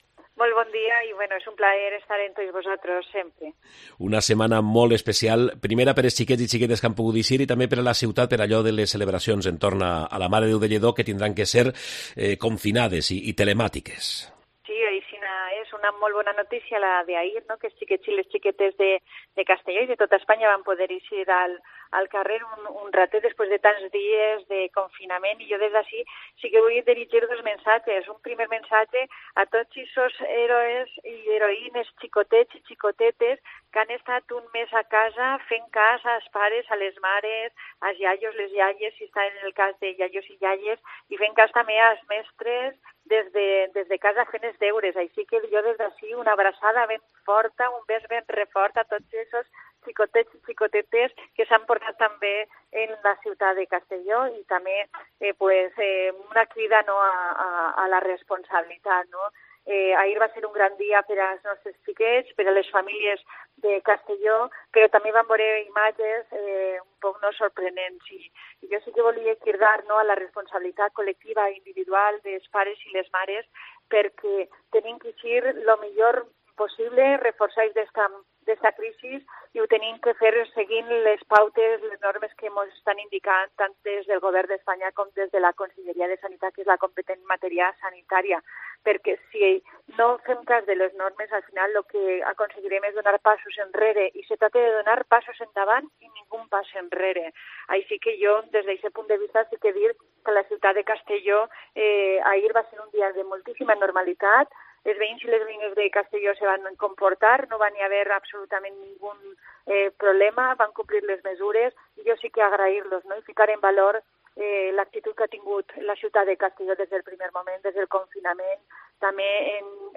La alcaldesa de Castellón, Amparo Marco, analiza en COPE la situación que vive la ciudad